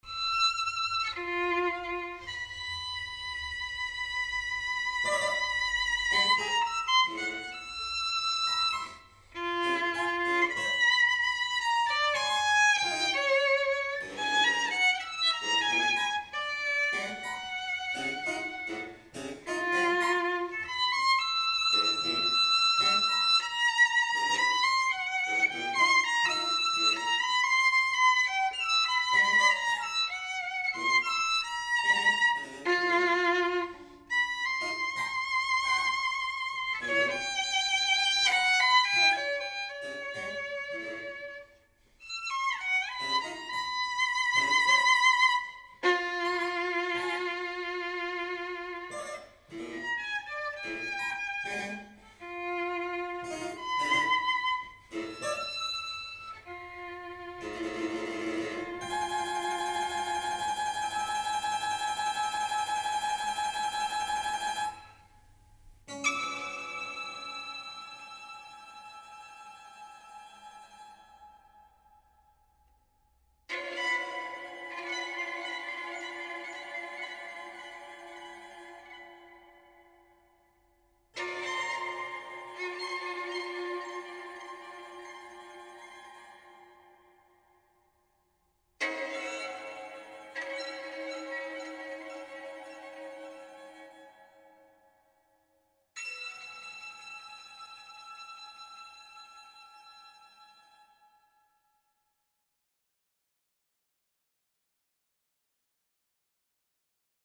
Musica per balletto
Per voce recitante e canto
flauto e ottavino
arpa
oboe
violino
pianoforte e clavicembalo
chitarra e percussioni